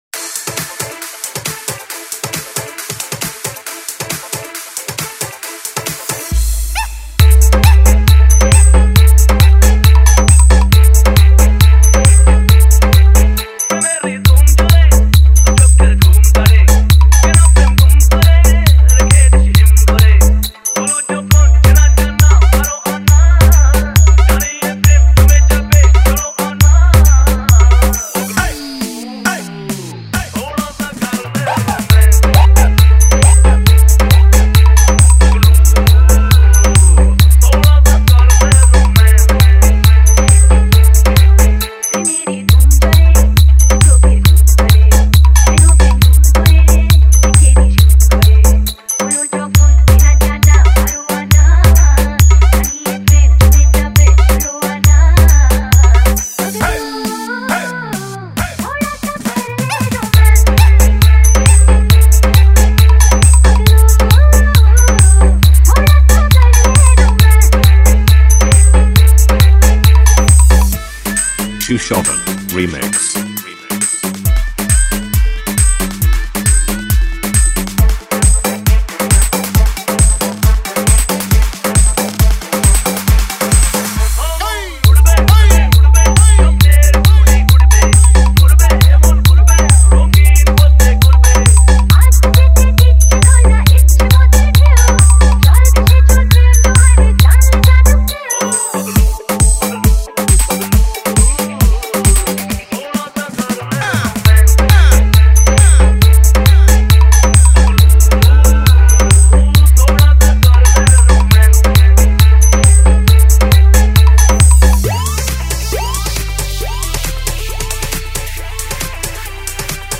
Humming Dance Mix
Lakshmi Puja SpL 1 Step Bhakti Song Wait Humming Mix 2024